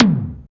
HARD-E-TOM.wav